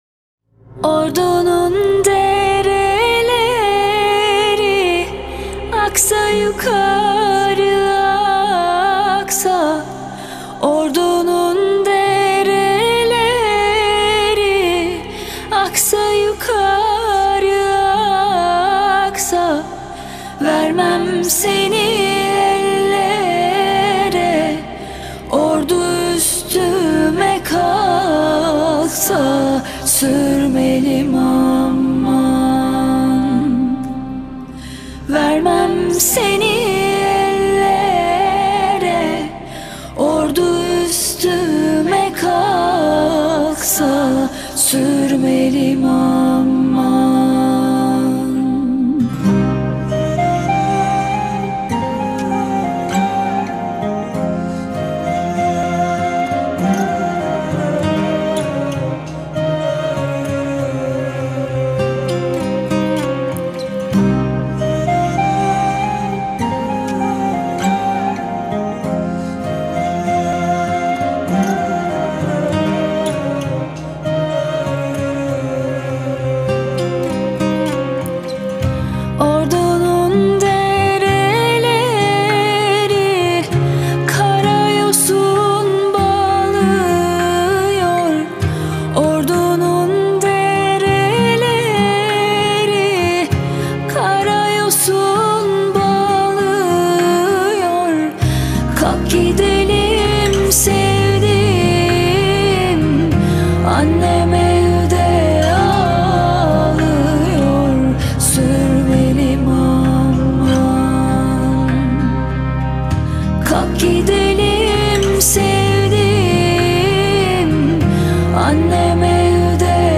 duygusal hüzünlü üzgün şarkı.